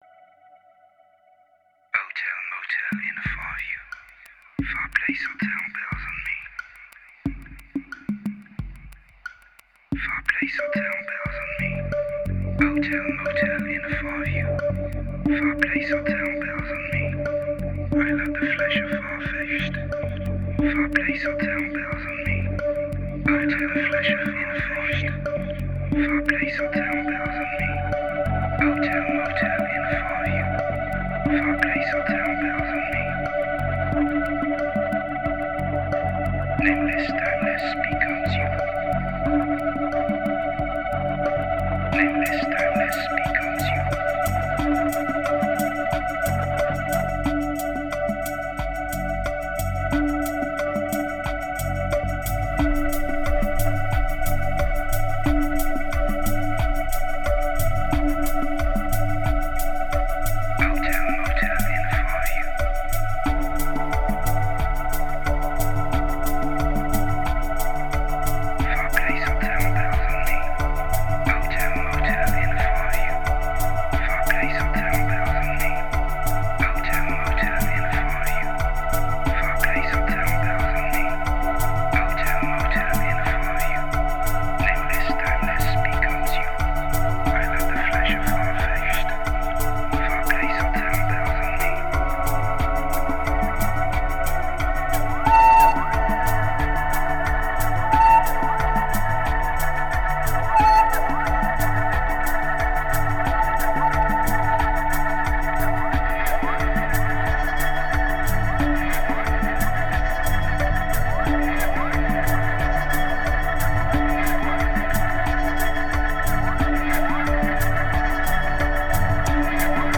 2216📈 - 92%🤔 - 90BPM🔊 - 2014-09-29📅 - 228🌟